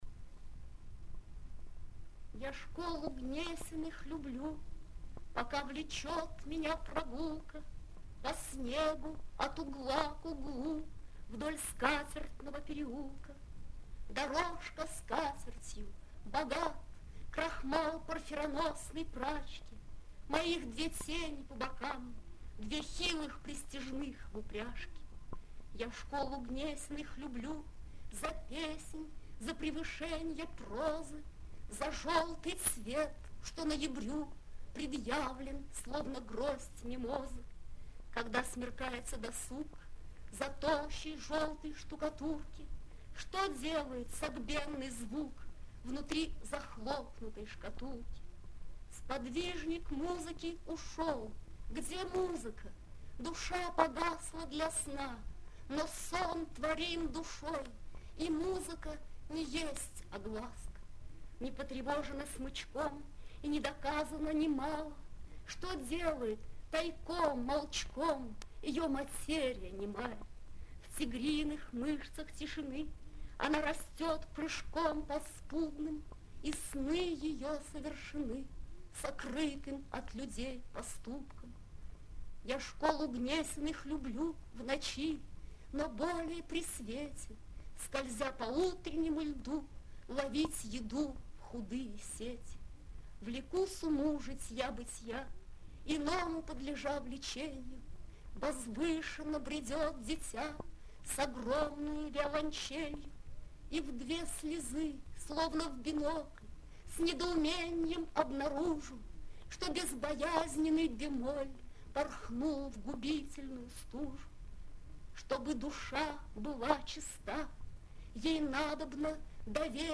bella-ahmadulina-ya-shkolu-gnesinyh-lyublyu-chitaet-avtor